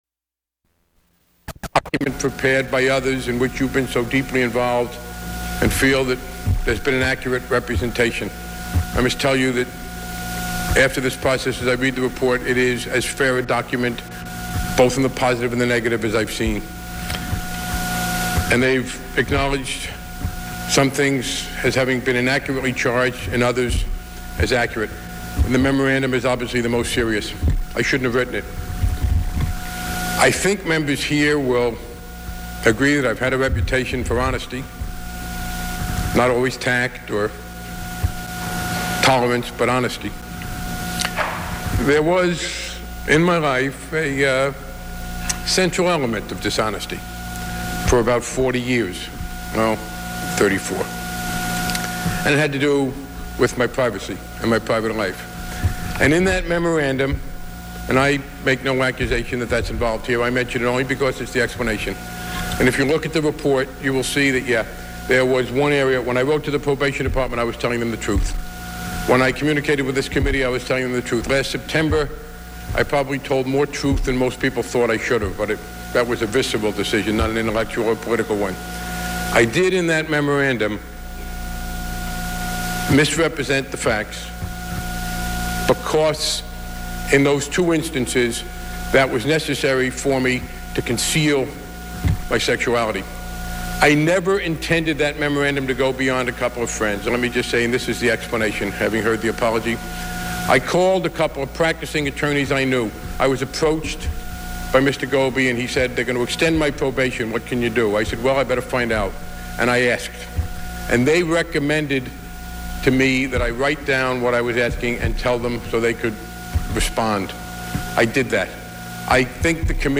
U.S. Congressman Barney Frank addresses the House of Representatives after his reprimand for unprofessional behavior